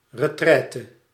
Ääntäminen
Vaihtoehtoiset kirjoitusmuodot (vanhentunut) retrait Synonyymit ark back up retirement sanctuary back down back off fallback back away fall back resting place bug out place of refuge home of rest Ääntäminen US